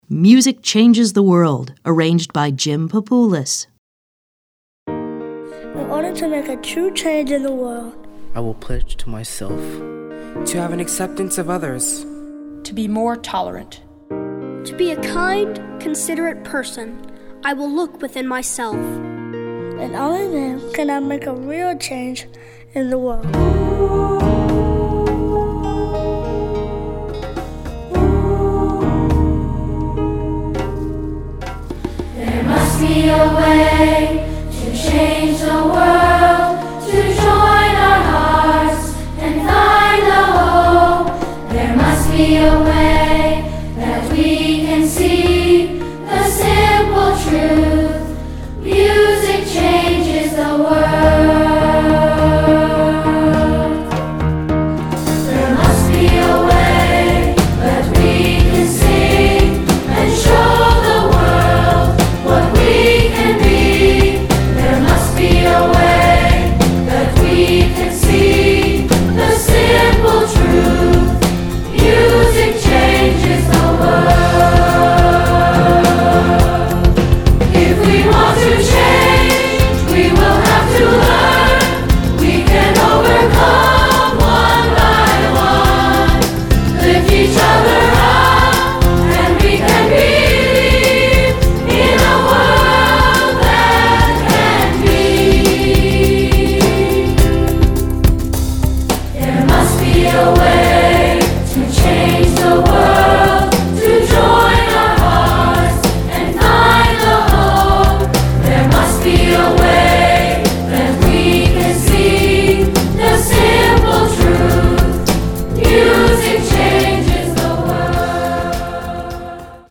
Choral Concert/General Women's Chorus
SSA